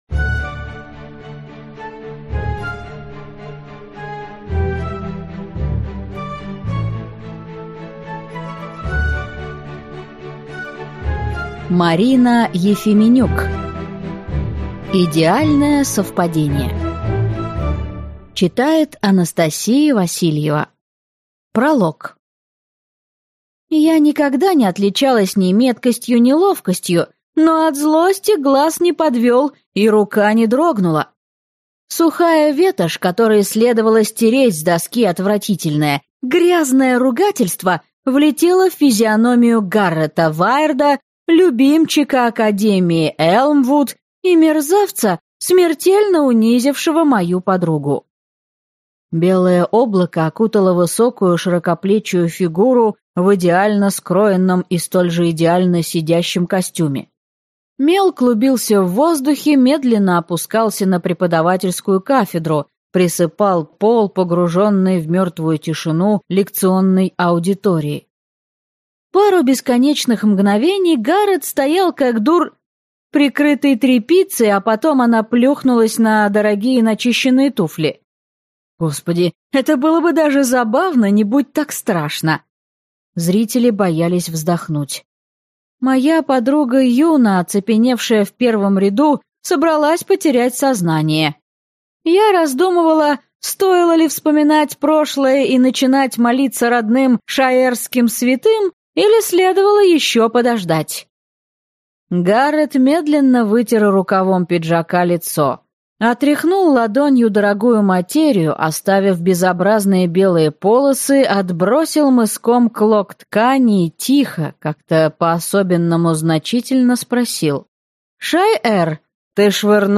Аудиокнига Идеальное совпадение | Библиотека аудиокниг
Прослушать и бесплатно скачать фрагмент аудиокниги